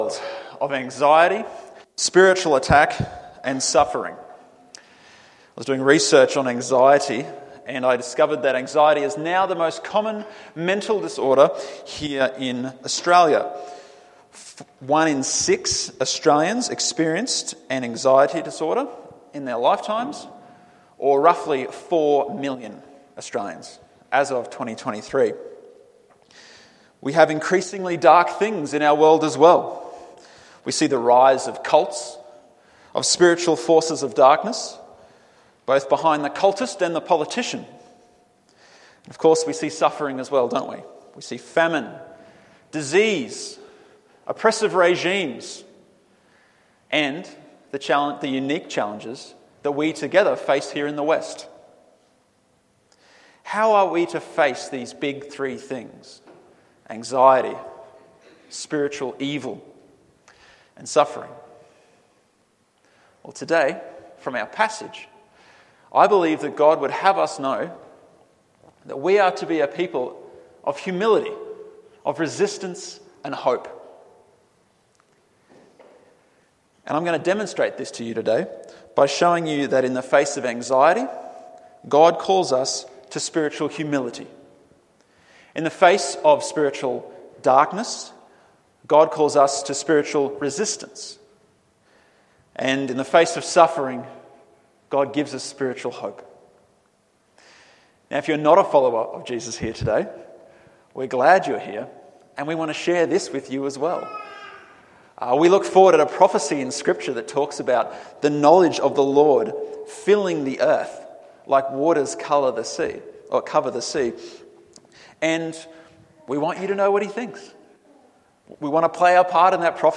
Expository and topical preaching from Eastgate Church.